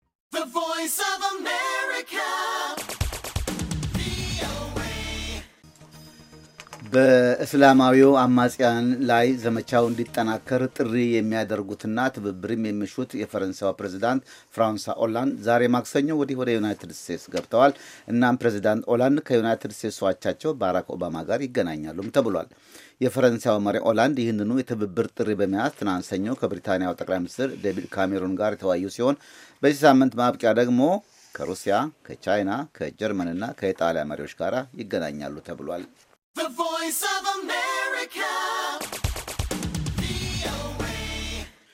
ፈረንሳይ ሰፊና የተጠናከረ የአየር ጥቃት ለማካሄድ ያወጣችውን ዕቅድ ዩናይትድ ስቴትስ እንደምትደግፈው የኋይት ሀውሱ ቃል-አቀባዩ ጆሽ እርነስት (Josh Earnest) አመልክተዋል። ዛሬ አጭር ዜና ይዘን ቀርበን ነበር ከተያያዘው የድምጽ ፋይል ያዳምጡ።